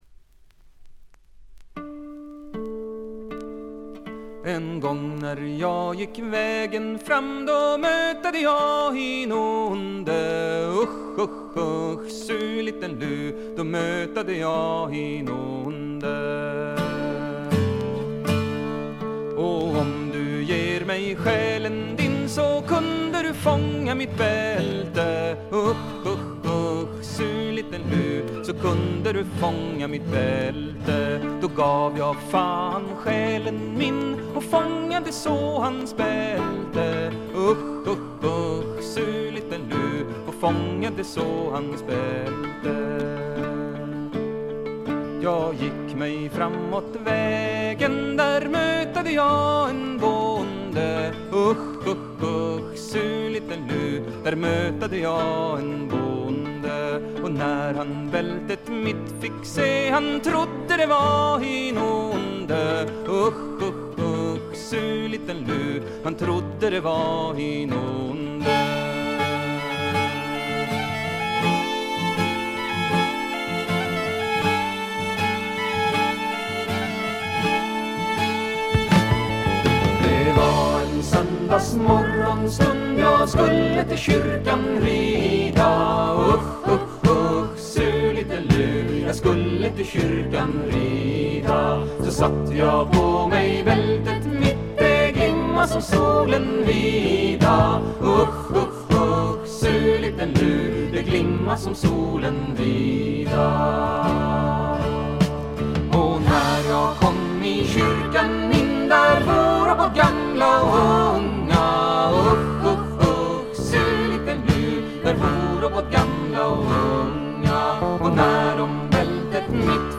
これ以外は軽微なバックグラウンドノイズ程度で全編良好に鑑賞できると思います。
スウェーデンのトラッド・フォーク・グループ。
ずばりスウェーデン産トラッド・フォークの名盤にしてフィメール・フォークの名盤でもあります。
重厚でどっしりとしたバンド・サウンドが味わえます。
試聴曲は現品からの取り込み音源です。
Vocals, Violin, Hardingfele, Percussion
Accordion, Oboe
Acoustic Bass